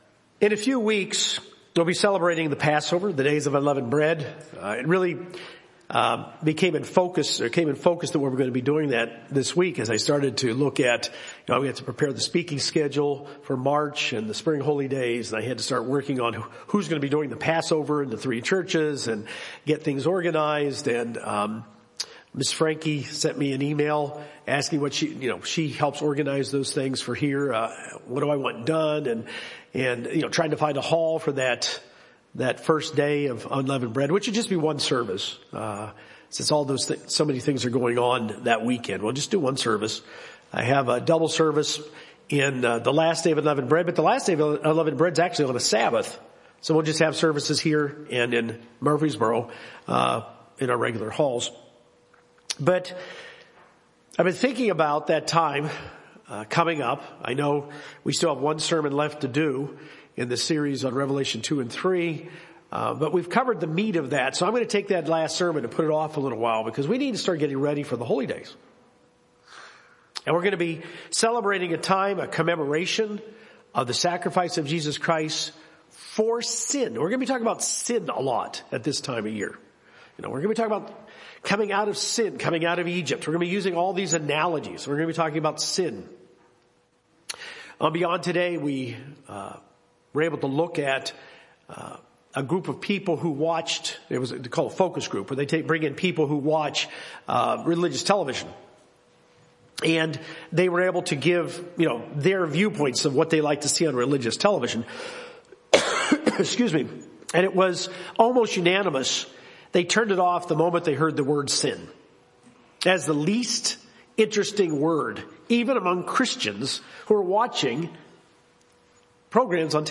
In order to examine ourselves in preparation for Passover, we should understand what sin is so we can properly identify it in our lives. This sermon examines four scriptures in the New Testament that define sin and fleshes them out in a modern, daily context.